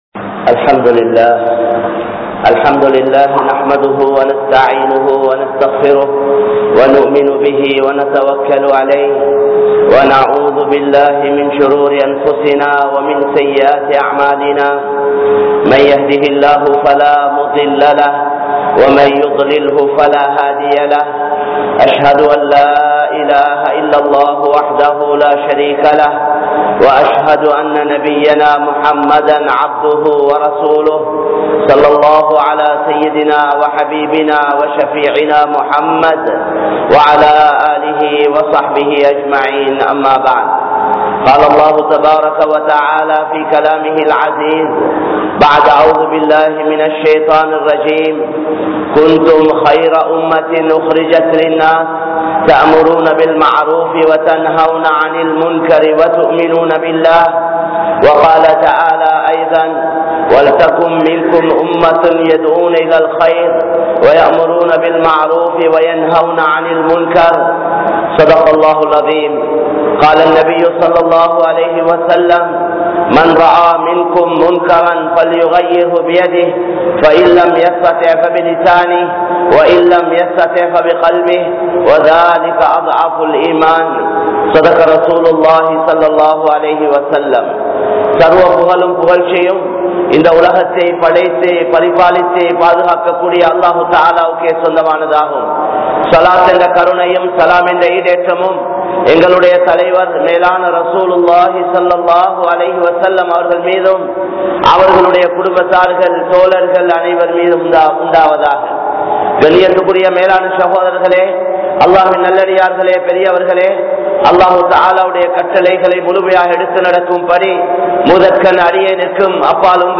Allah`vin Pakkam Makkalai Alaiungal (அல்லாஹ்வின் பக்கம் மக்களை அழையுங்கள்) | Audio Bayans | All Ceylon Muslim Youth Community | Addalaichenai
Rajagiriya, Nooraniya Jumua Masjidh